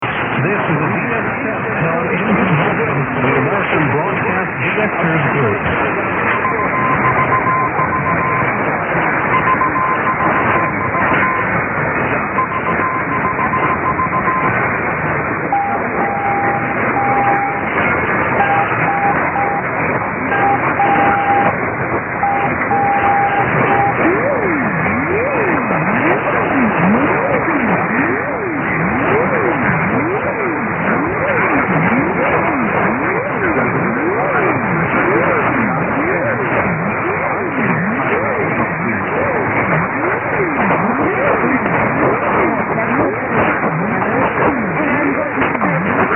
WSAR 1480 DX TEST: A sudden DX test was announced mid week from one of the common stations here in Europe, WSAR, Fall River. Noted here at 0500 with ids, and ann, but never noted the CW till just after 0600UTC, when there was some CW. WHBC Canton also heard, but WSAR was quite good here around 0602.